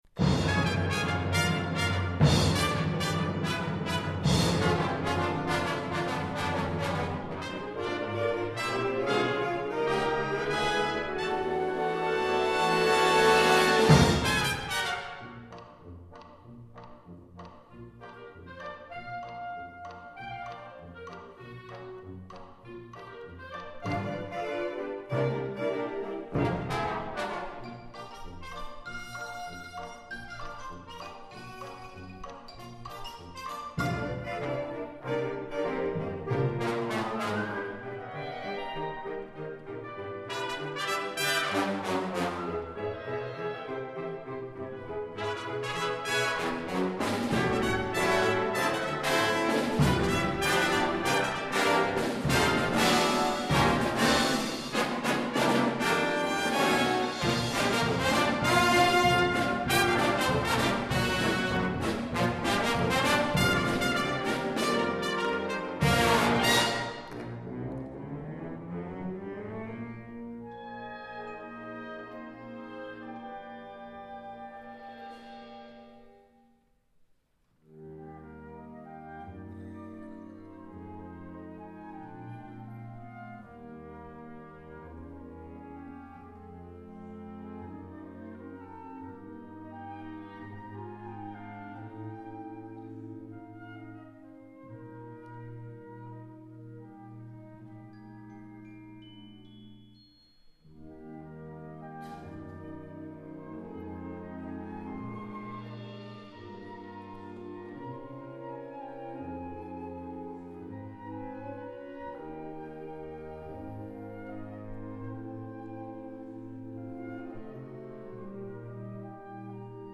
Répertoire pour Harmonie/fanfare - Orchestre D'Harmonie